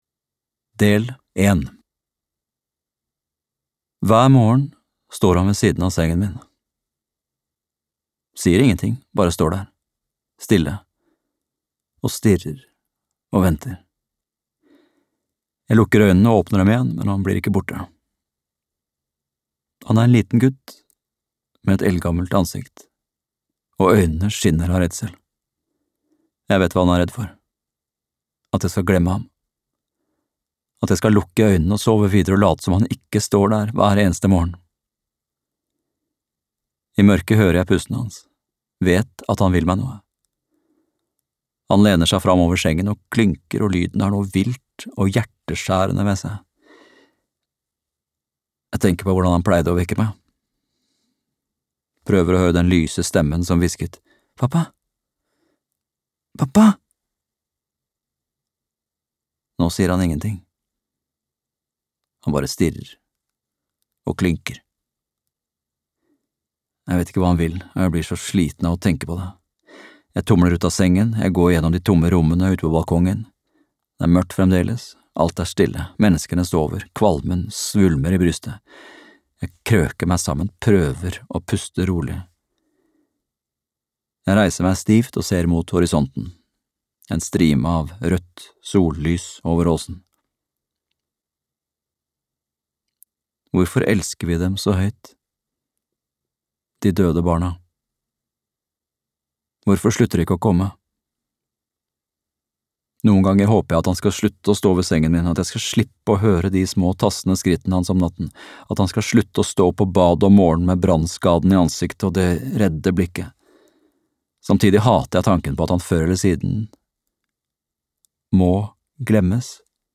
Utryddelsen - roman (lydbok) av Nikolaj Frobenius